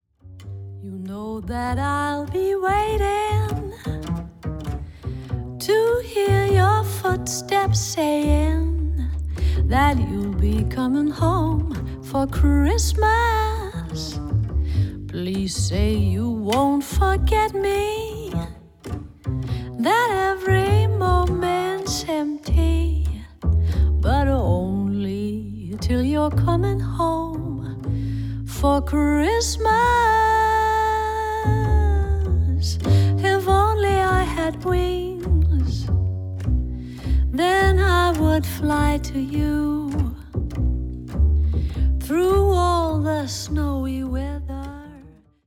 録音：2014年 ミュンヘン